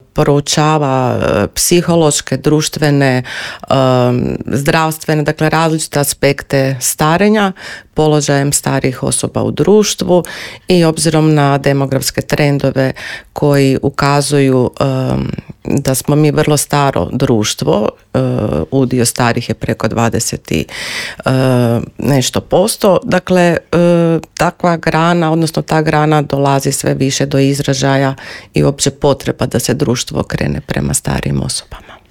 Gostujući u Intervjuu Media servisa objasnila je da je gerontologija znanstvena disciplina koja u svom fokusu ima osobe starije životne dobi.